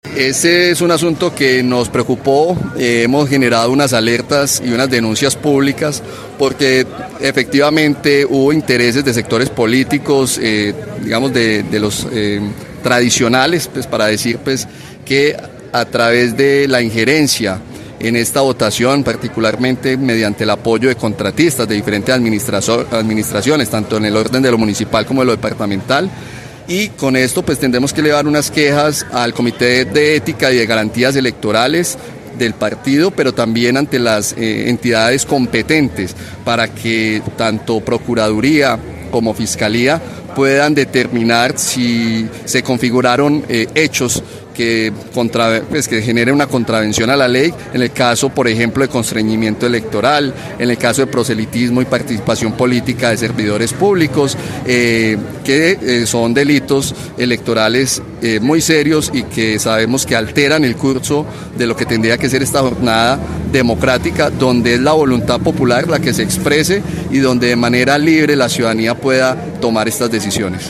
Jhonatan Rojo, concejal del Pacto Histórico de Amrenia